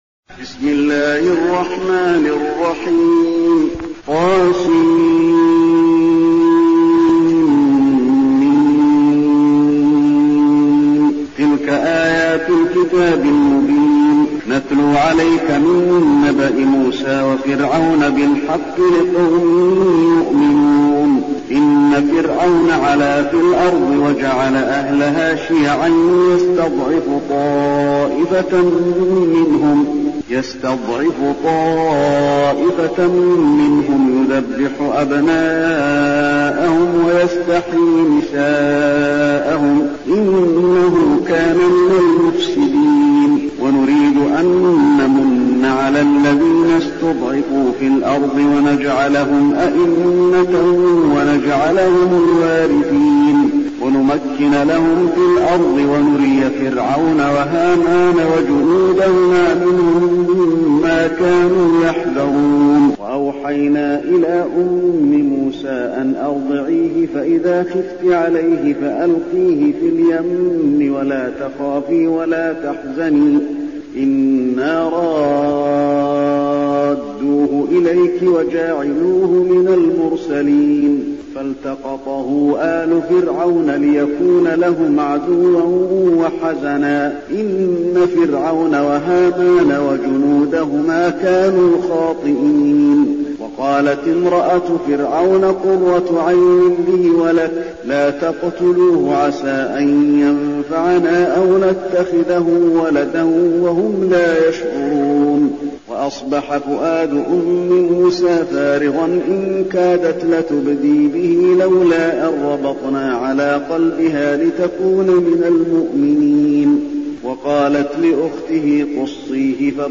المكان: المسجد النبوي القصص